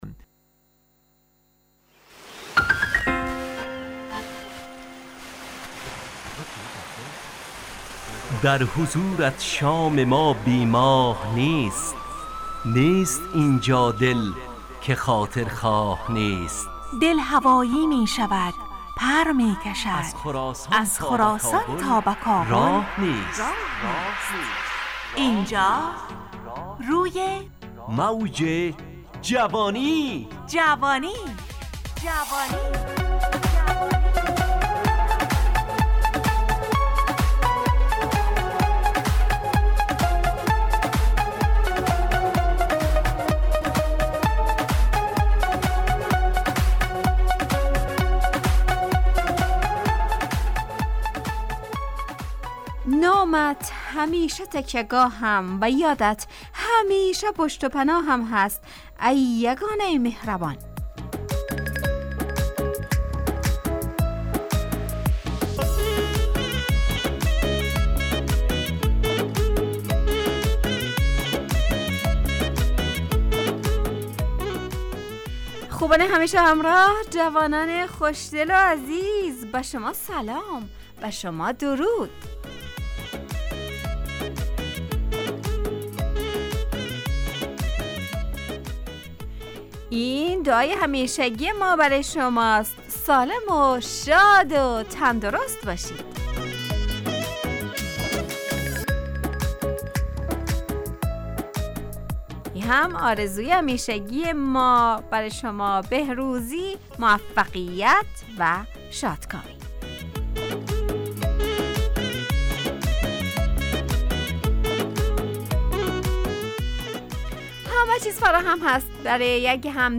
همراه با ترانه و موسیقی مدت برنامه 55 دقیقه . بحث محوری این هفته (شتاب و عجله ) تهیه کننده